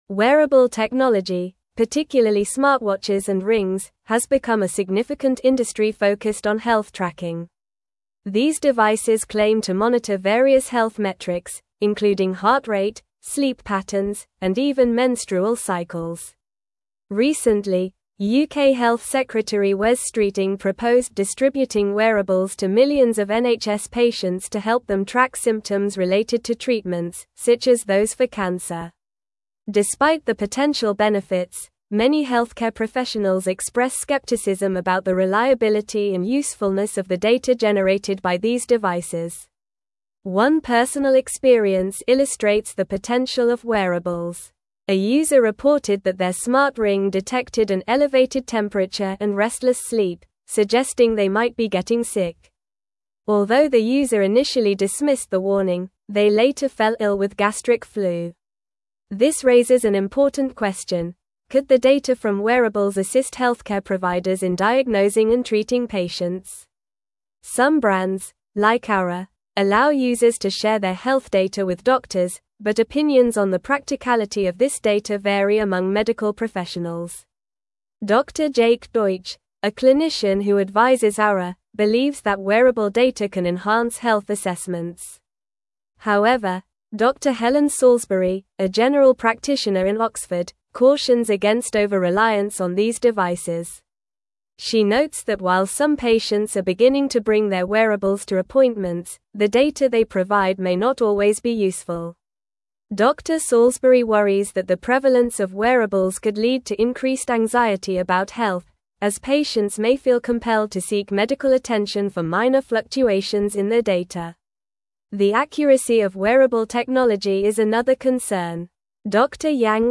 Normal
English-Newsroom-Advanced-NORMAL-Reading-Wearable-Technologys-Impact-on-Healthcare-Benefits-and-Concerns.mp3